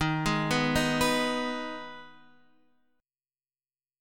Ebsus4#5 chord